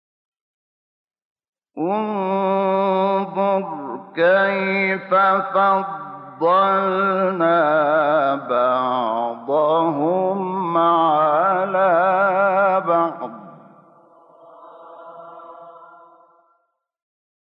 شحات انور- بیات حسینی -سایت سلیم 2014 (2).mp3